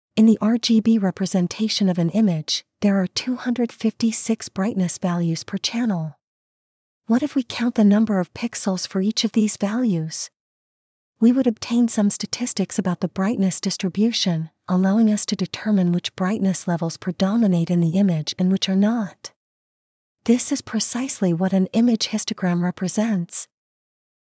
Для ценителей ASMR, модель en-US JennyNeural whispering:
Azure Speech Service классный, но платный и медленный — нам такое не подходит, идём дальше.